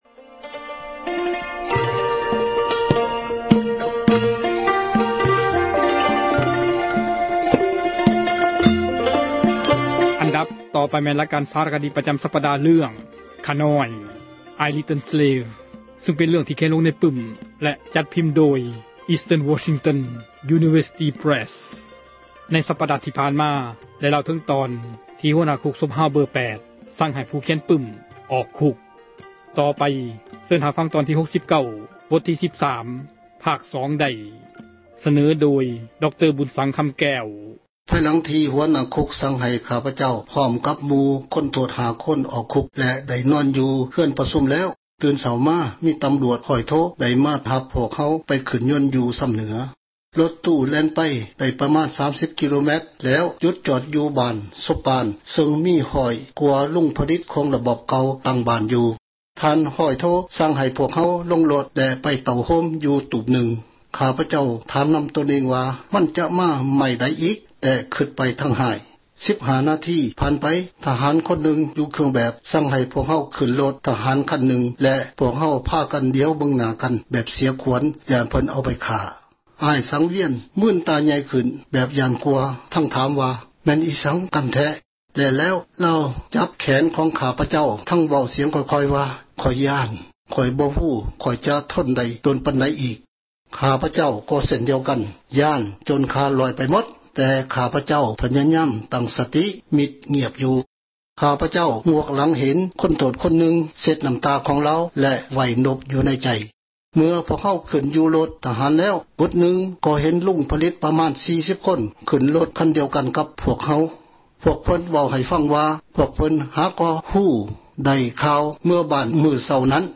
ສາຣະຄະດີ ເຣື່ອງ ”ຂ້ານ້ອຍ"